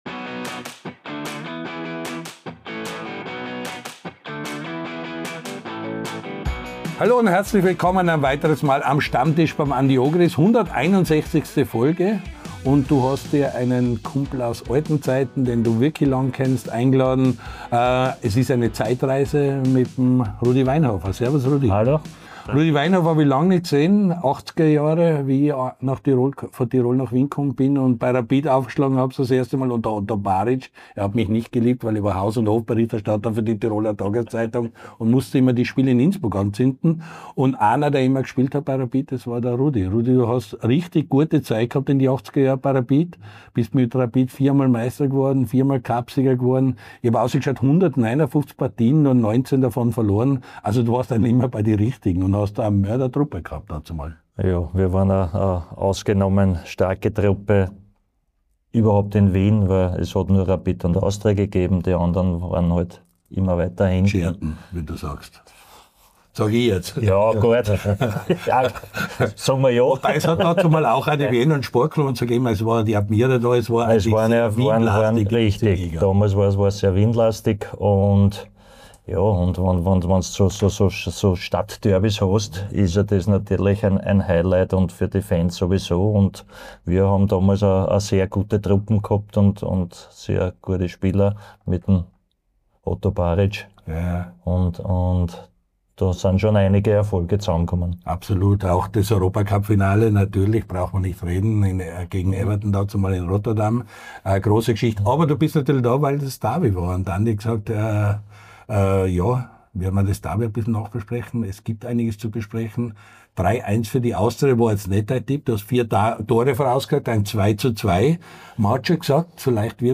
Am LAOLA1-Stammtisch diskutieren Andy Ogris und Rapid-Legende Rudi Weinhofer über das 347. Wiener Derby, Österreichs Europacupstarter und die "gute alte Zeit".